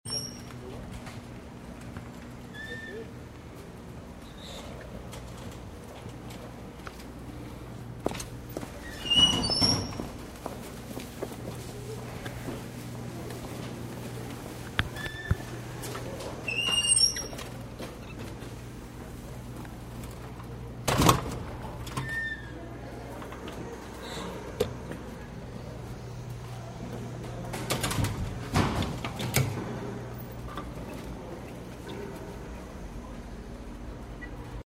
test-door-sound.mp3